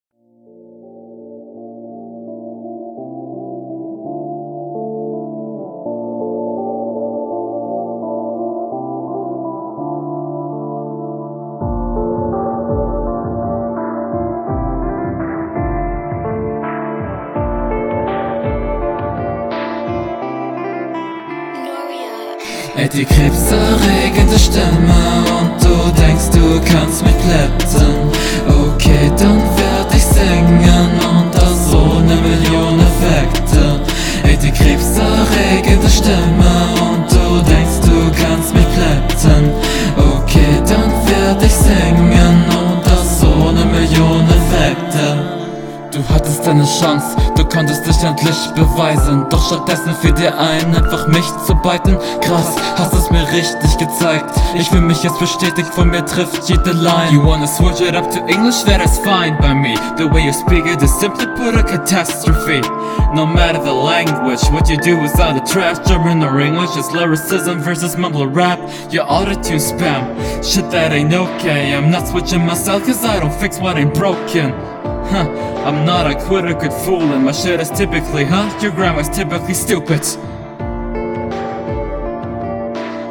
Wusste ja nicht einmal dass du singen kannst aber Probs du hast den Sieg verdient